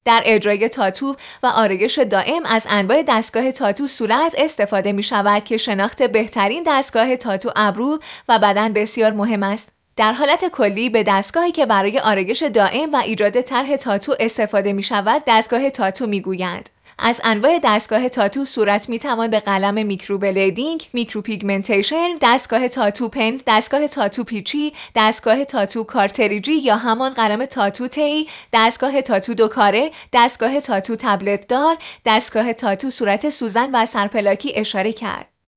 face-tatto-machine.mp3